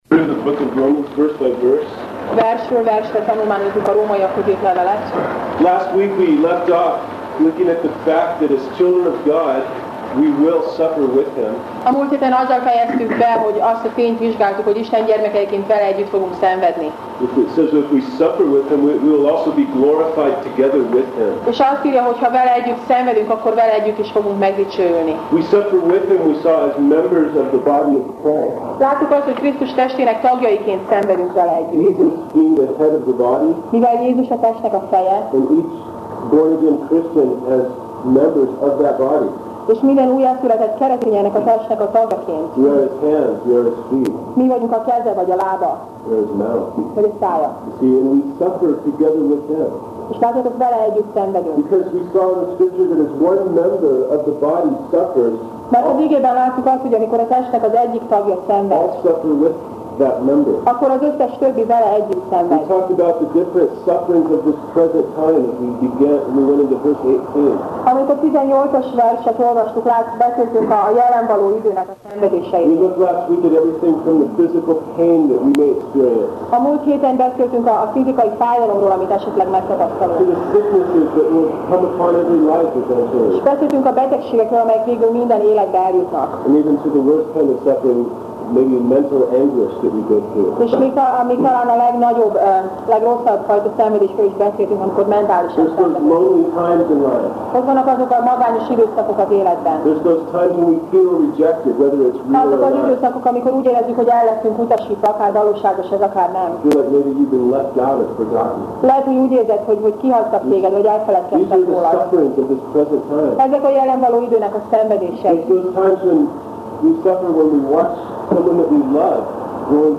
Róma Passage: Róma (Romans) 8:18-23 Alkalom: Vasárnap Reggel